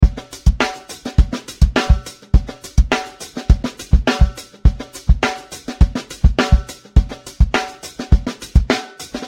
Brazilian Funk